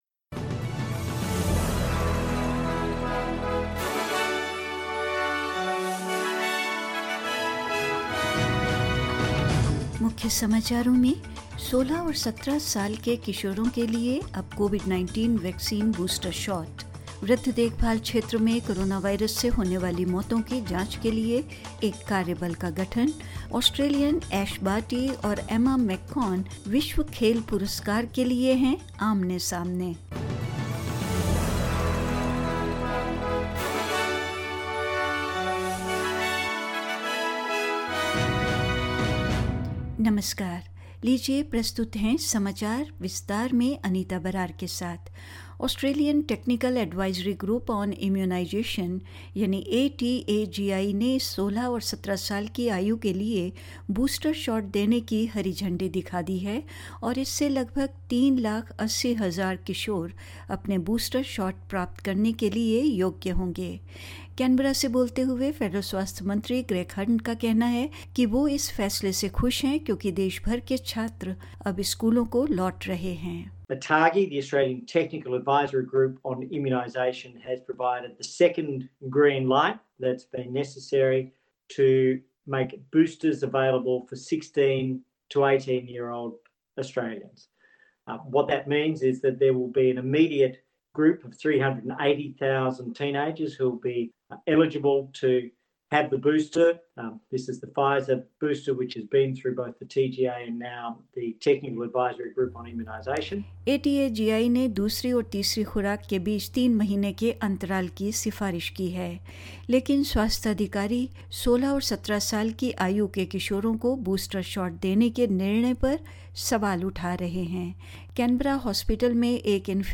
In this latest SBS Hindi bulletin: Teenagers aged 16 and 17 can now receive the COVID-19 vaccine booster; A taskforce will be set up to look into coronavirus deaths in the aged care sector; Australians Ash Barty and Emma McKeon go head-to-head for a global sports prize and more news.